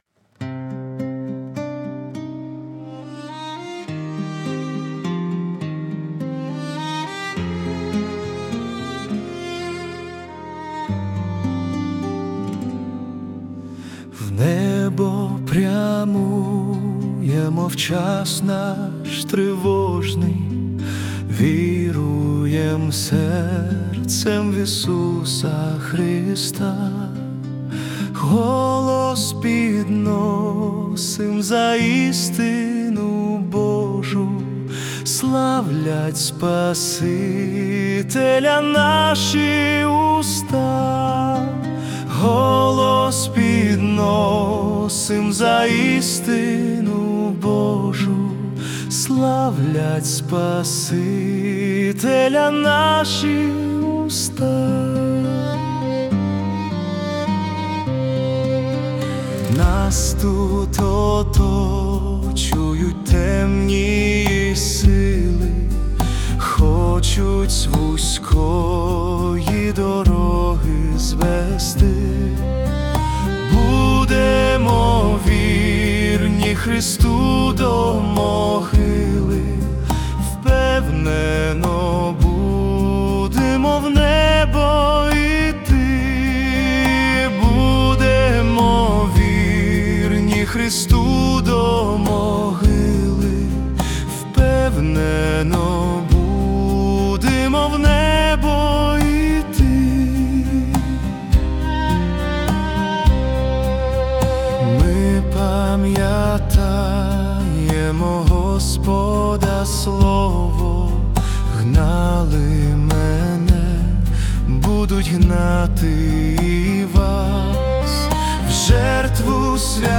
песня ai
14 просмотров 56 прослушиваний 5 скачиваний BPM: 103